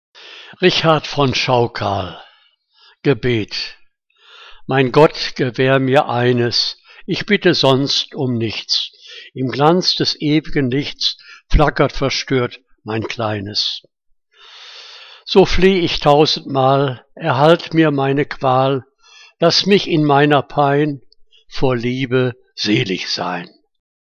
Liebeslyrik deutscher Dichter und Dichterinnen - gesprochen (Richard von Schaukal)